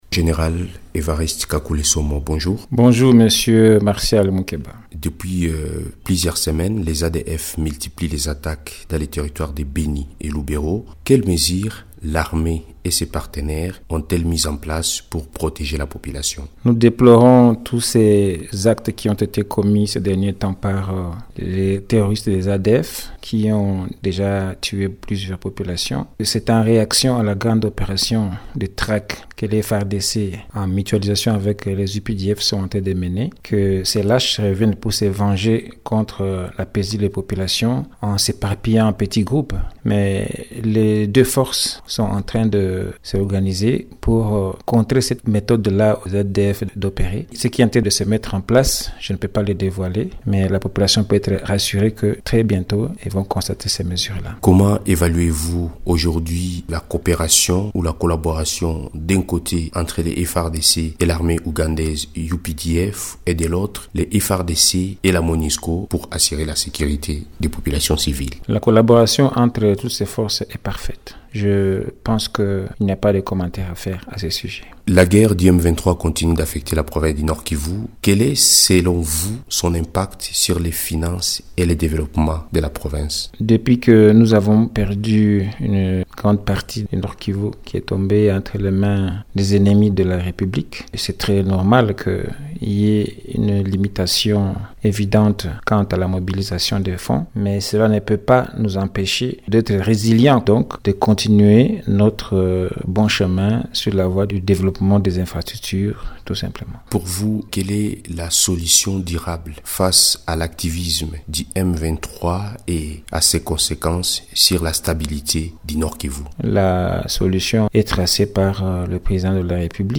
Pour faire à ces enjeux et envisager des pistes de sortie, le gouverneur du Nord-Kivu, le général Evariste Kakule Somo, évoque la nécessité d’une approche intégrée, combinant sécurité, développement et assistance humanitaire, avec le soutien du gouvernement central et des partenaires internationaux.